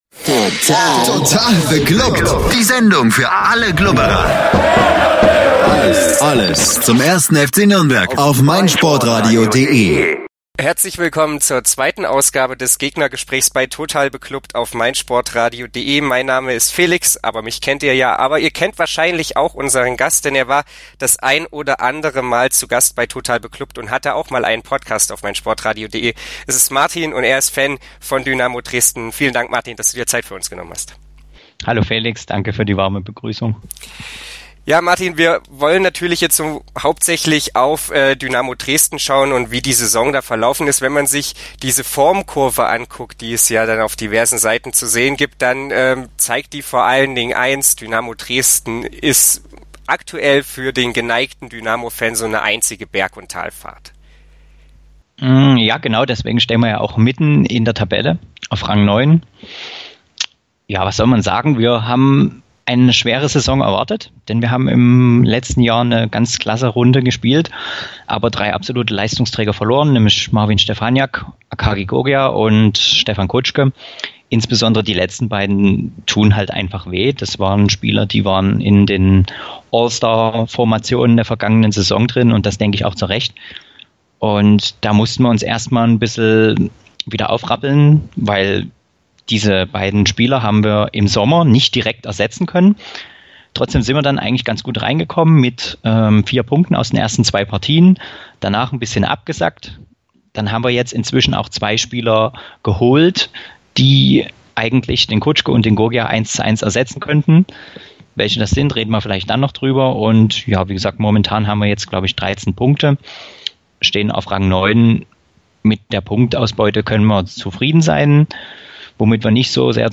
gegnergespraech-dresden-fcn.mp3